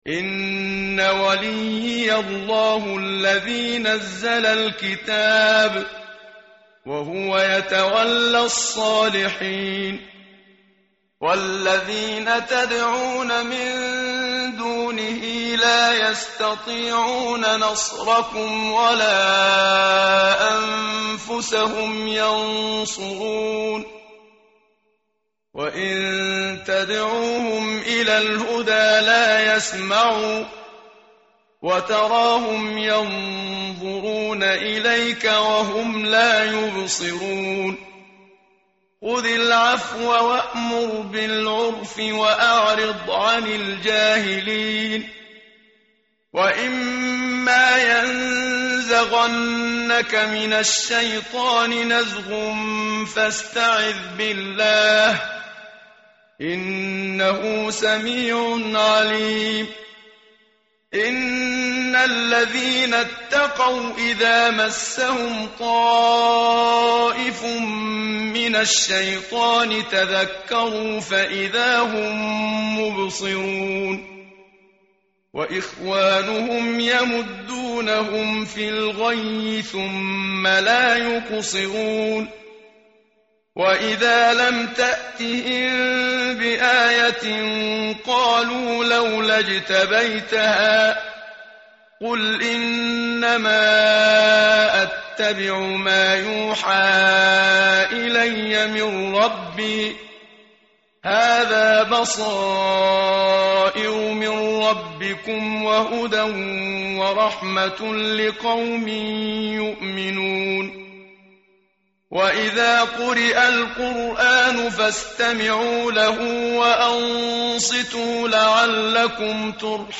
tartil_menshavi_page_176.mp3